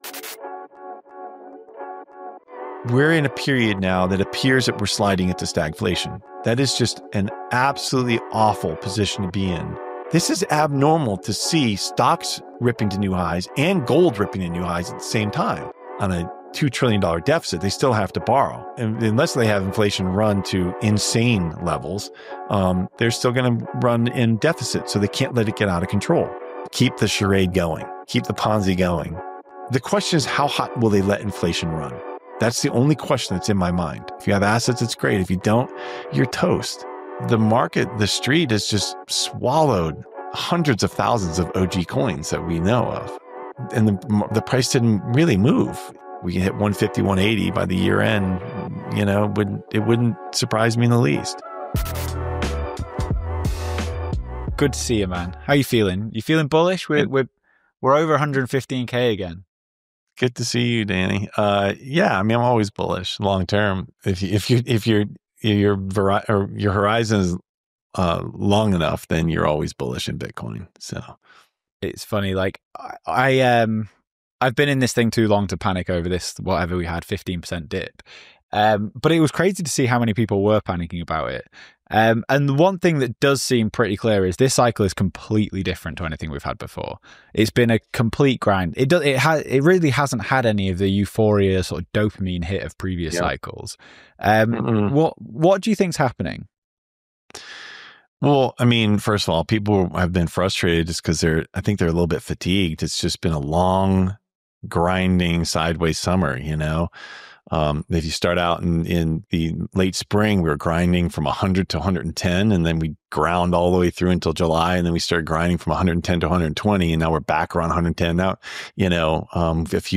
In this interview, we discuss the Strategic Bitcoin Reserve, the feasibility of executive action to acquire Bitcoin, and the implications for U.S. financial policy. We also get into Bitcoin's role as a monetary system, the potential for nation-state adoption, and whether fiat currencies can coexist with Bitcoin.